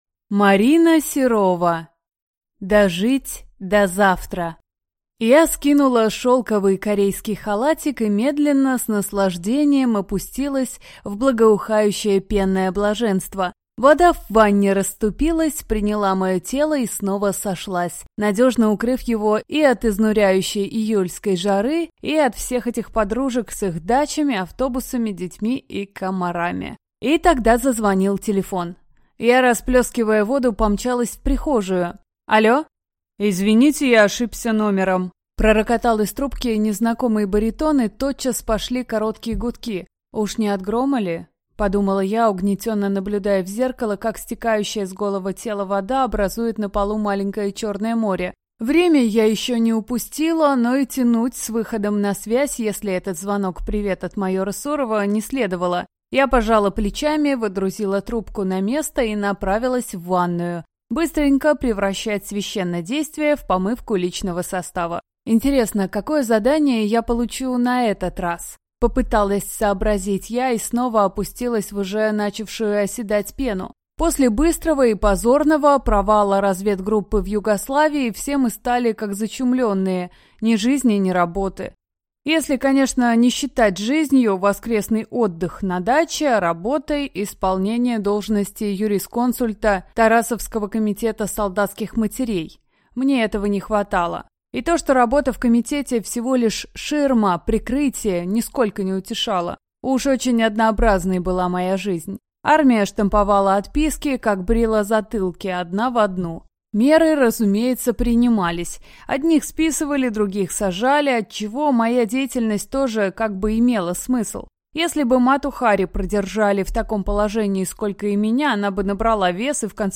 Аудиокнига Дожить до завтра | Библиотека аудиокниг